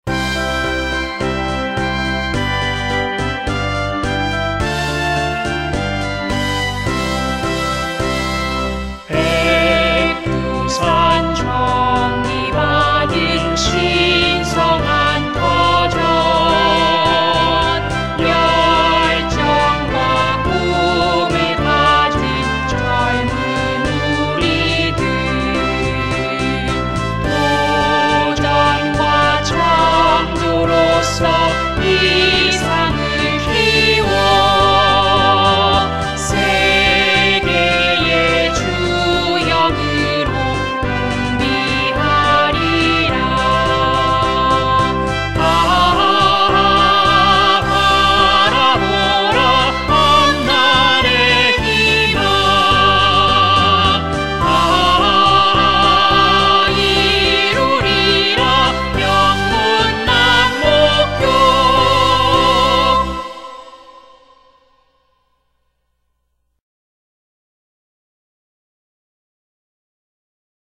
남목고등학교 교가 음원 :울산교육디지털박물관
남목고등학교의 교가 음원으로 금난새작곡,조용대작사이다....